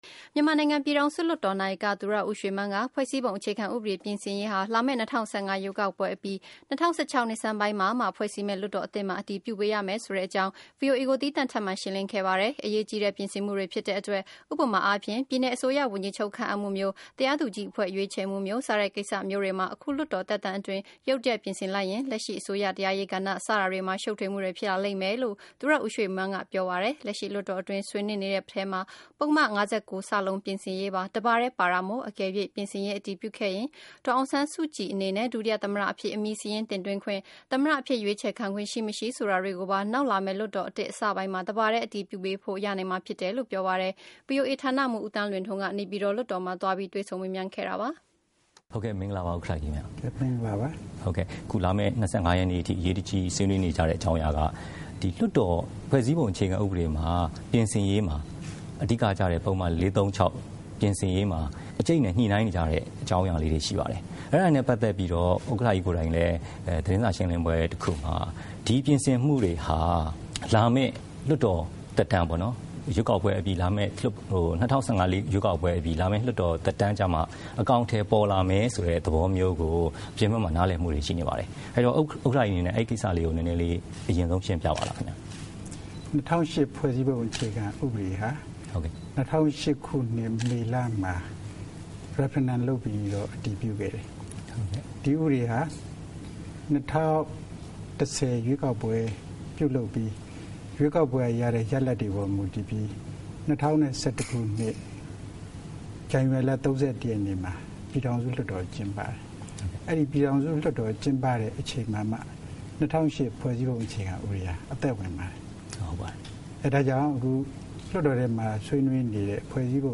ပြည်ထောင်စု လွှတ်တော် နာယက သူရဦးရွှေမန်း နဲ့ ဗွီအိုအေ သီးသန့် တွေ့ဆုံ မေးမြန်းခန်း (အပြည့်အစုံ)